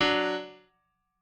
piano1_21.ogg